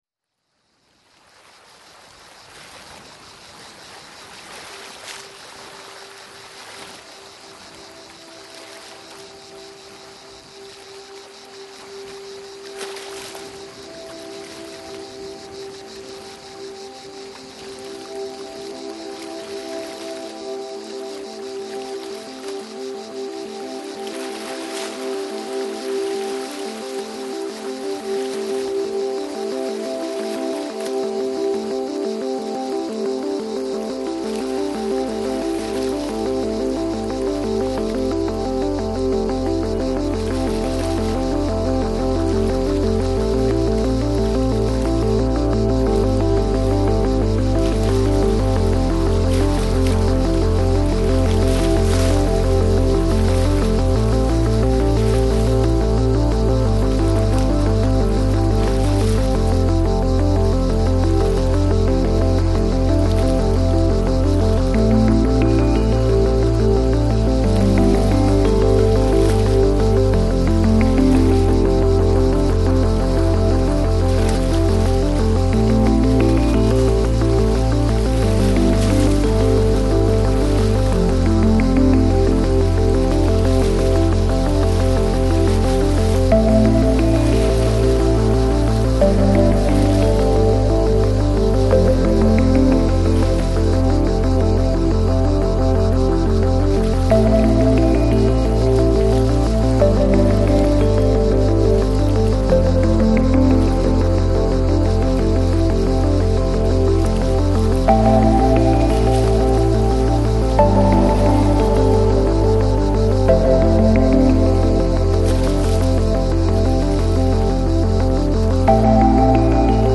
Жанр: Downtempo, Lounge, Chill Out